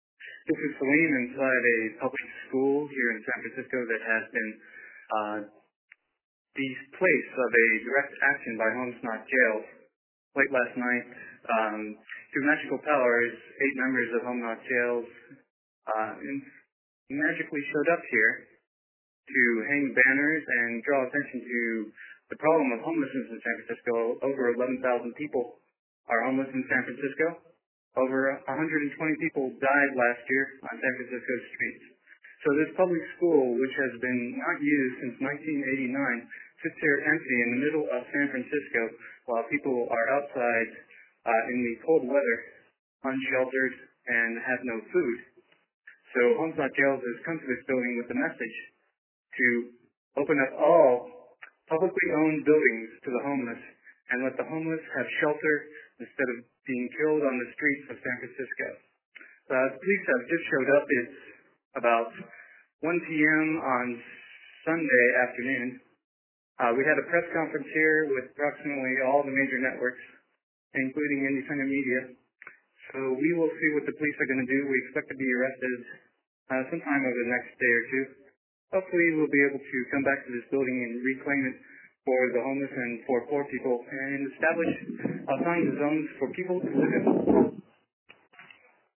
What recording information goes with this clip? here is an audio update made by one of the people squatting at 170 Fell Street right now, 1pm sunday